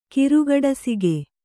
♪ kirugaḍasige